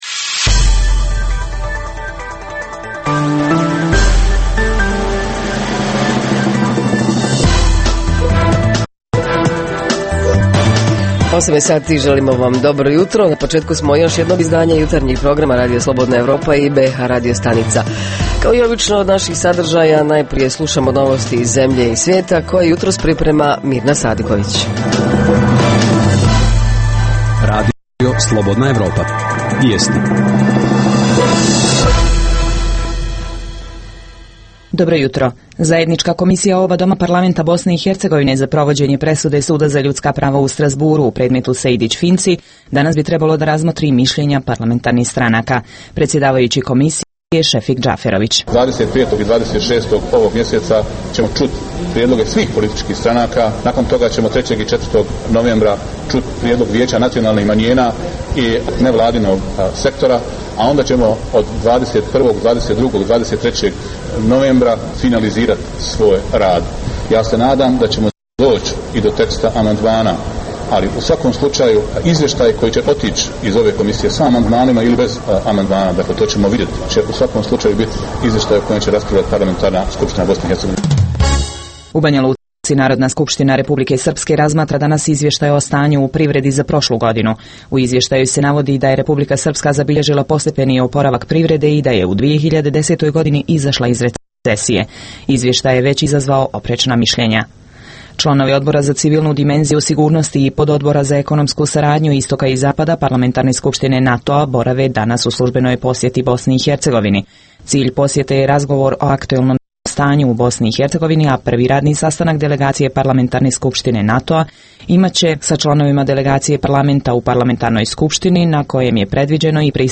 - U 2011. godini širom svijeta obilježava se 250 godina veterinarske profesije, pa ćemo ovog jutra prostor posvetiti upravo ovoj struci - radu veterinara, problemima s kojima se suočavaju i rezultatima njihovog rada. - Reporteri iz cijele BiH javljaju o najaktuelnijim događajima u njihovim sredinama.
- Redovni sadržaji jutarnjeg programa za BiH su i vijesti i muzika.